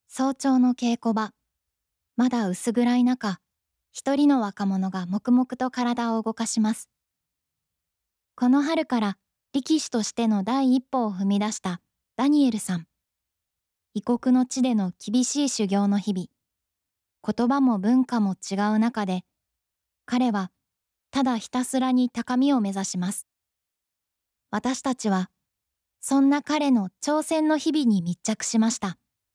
やわらかさ、透明感、温かみのある声です。
ナチュラル、自然な